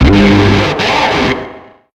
Grito de Abomasnow.ogg
Grito_de_Abomasnow.ogg